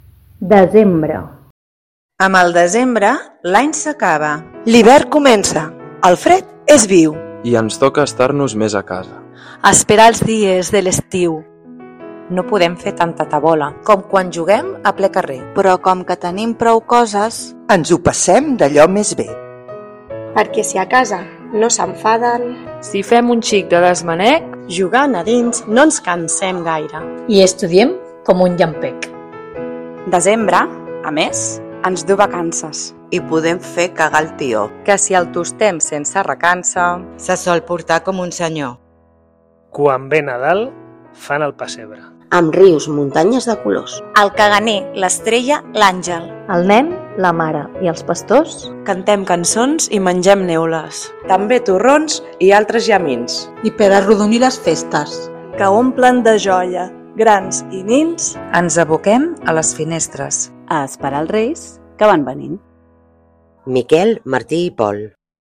L’equip del Damià Mateu us desitja bon Nadal i bon Any Nou recitant conjuntament el poema Desembre de Miquel Martí i Pol.
Poema-de-Nadal_mestres_2025.mp3